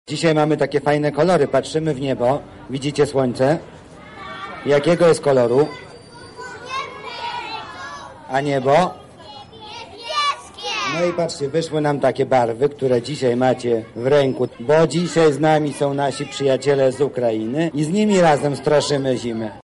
Małych uczniów przywitał Mariusz Banach, zastępca Prezydenta Miasta ds. Oświaty i Wychowania: